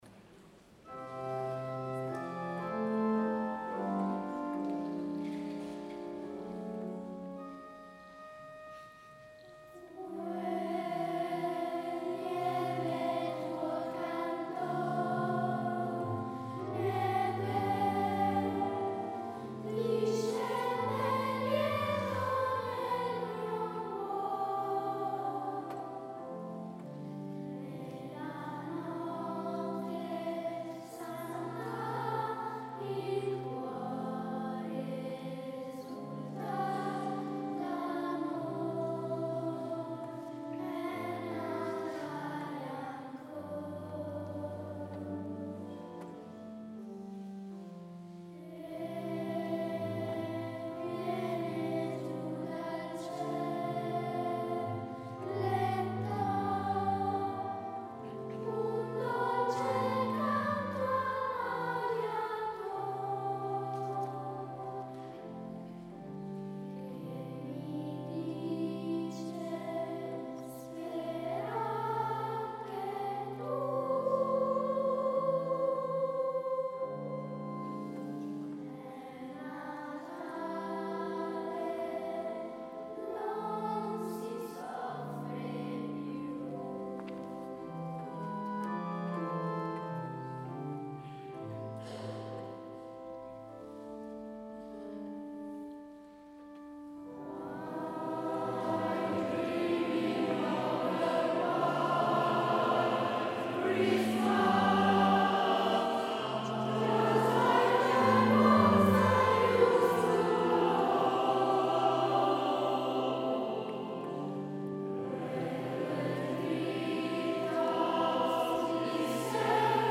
S. Gaudenzio church choir Gambolo' (PV) Italy
22 dicembre 2025 - Concerto di Natale
audio del concerto